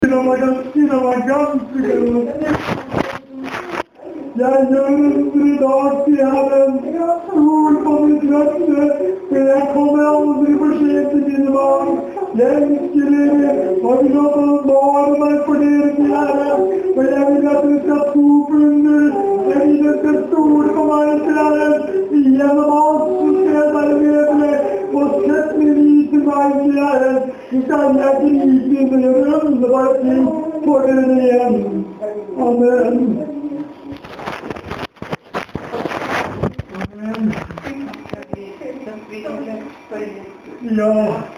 Sion Åheim, søndag 7.3.10.